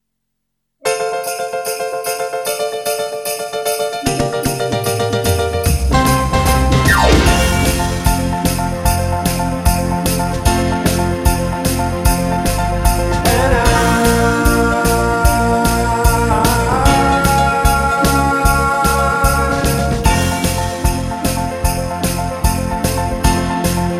Two Semitones Down Pop (1990s) 3:49 Buy £1.50